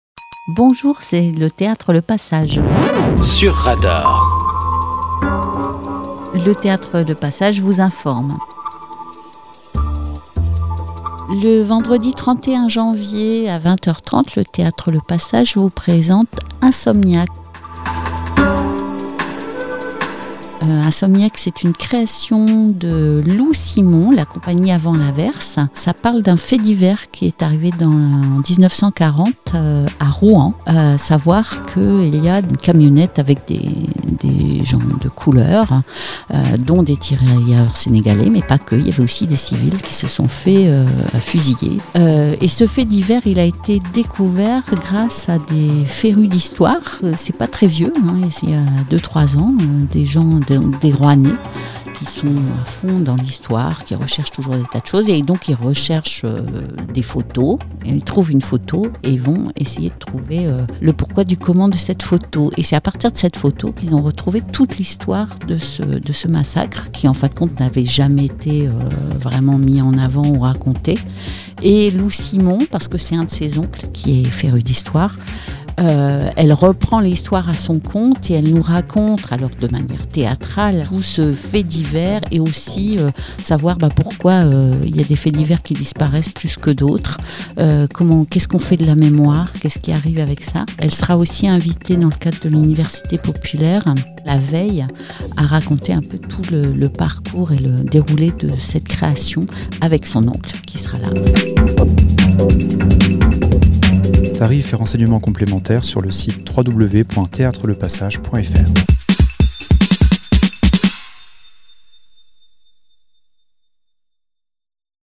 Régulièrement, différentes associations Fécampoises viennent dans nos studios pour enregistrer leurs différentes annonces pour vous informer de leurs activités